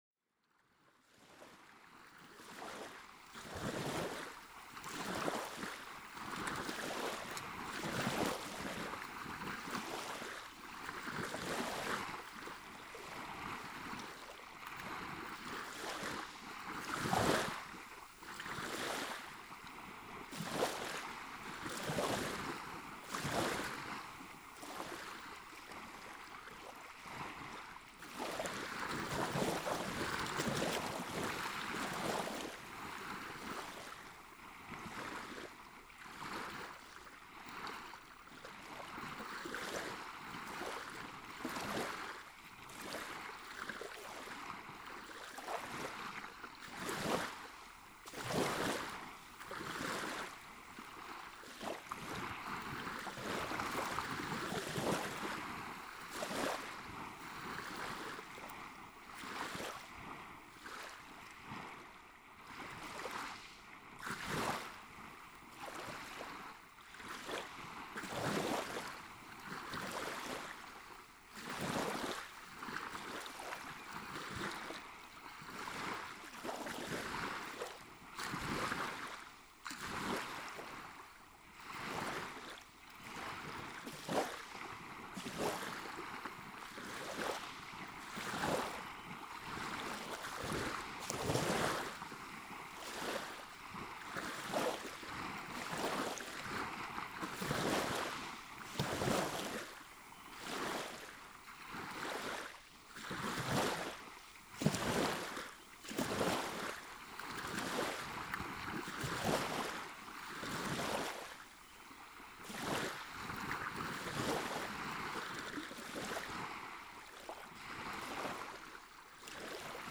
DPA4060 „binaural“ test
Posted in Óflokkað, tagged Binaural Array, Binaural recording, Cliff, DPA4060, Sea birds, Sound Devices 744, Test, Traffic noise, Waves on 10.7.2016| Leave a Comment »
I end up with a simple „binaural“ project I made out of wooden leftover (see pictures) .
It also gives me wider „stereo image“. But best of all, it withstands wind- and handling noise much better than previous BP4025 setup. Following recordings are 4 and were made in three locations.
dpa4060_binaural_sound_test.mp3